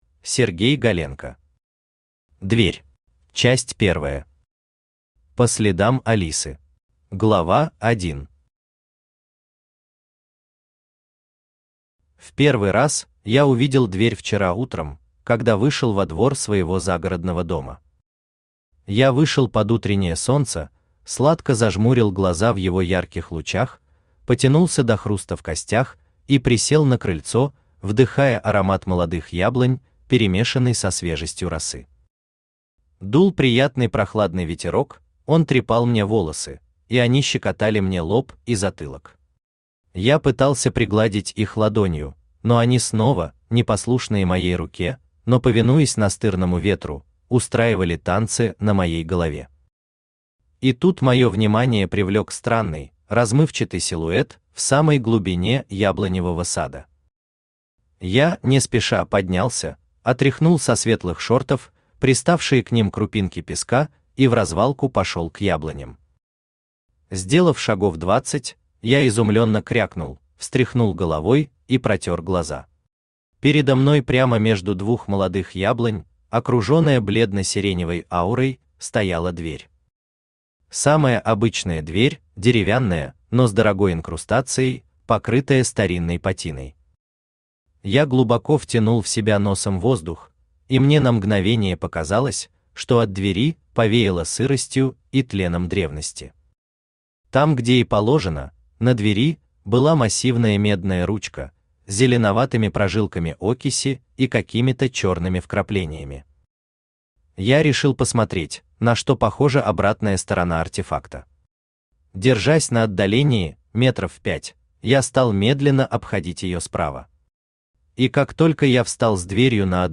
Аудиокнига Дверь | Библиотека аудиокниг
Aудиокнига Дверь Автор Сергей Анатольевич Галенко Читает аудиокнигу Авточтец ЛитРес.